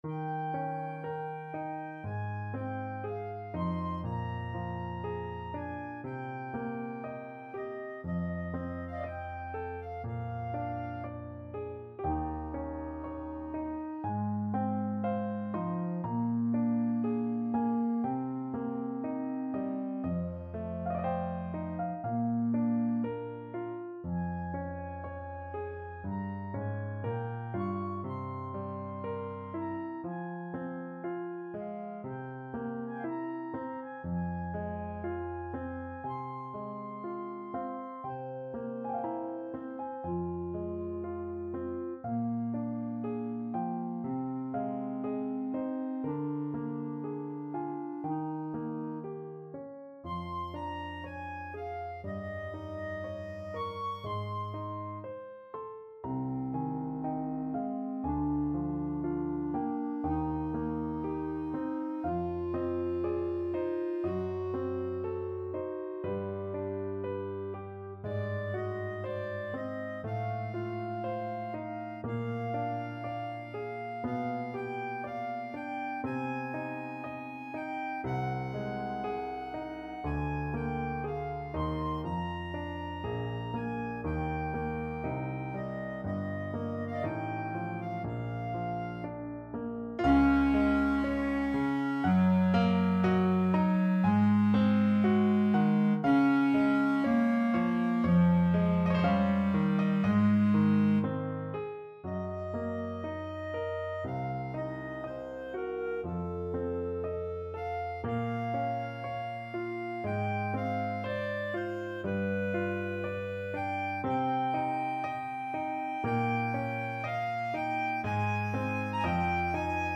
Classical Chopin, Frédéric Cello Sonata, Op.65 Third Movement, Largo Clarinet version
Clarinet
Eb major (Sounding Pitch) F major (Clarinet in Bb) (View more Eb major Music for Clarinet )
3/2 (View more 3/2 Music)
~ = 60 Largo
Classical (View more Classical Clarinet Music)